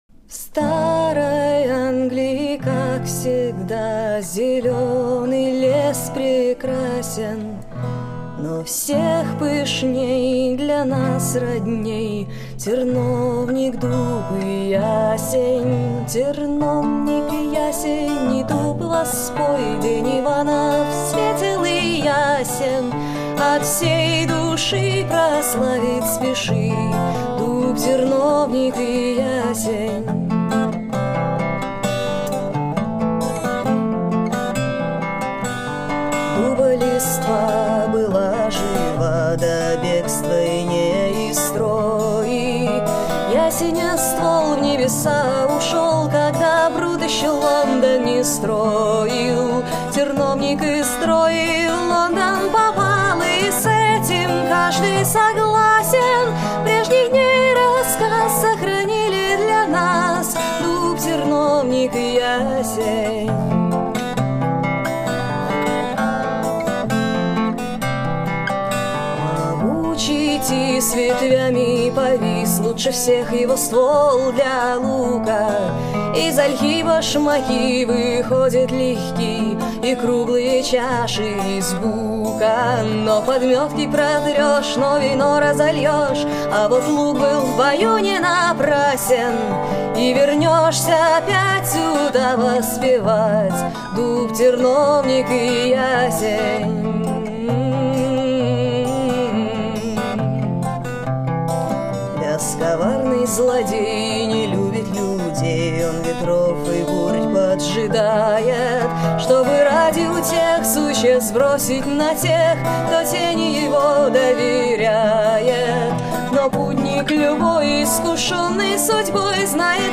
Фолк. Соло под гитару.